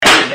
PLAY yoda gets slaped
yoda-slap.mp3